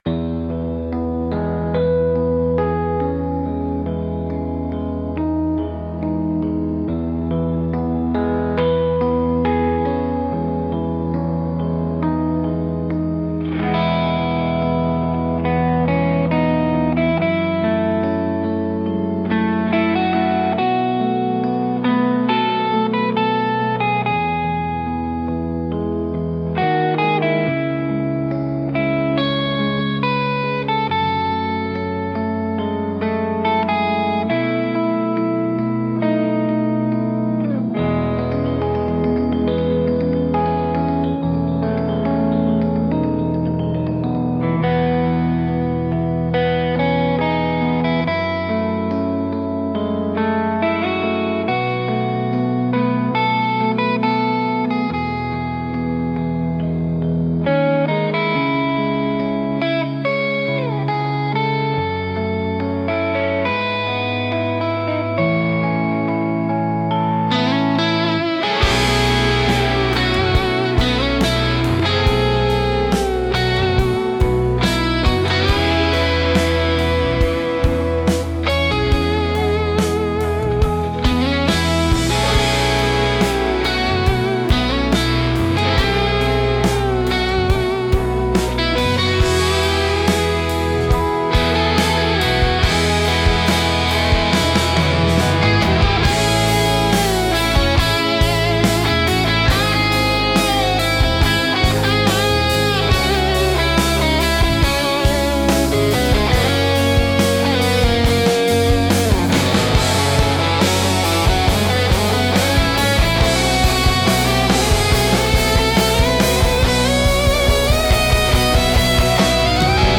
Инструментальная композиция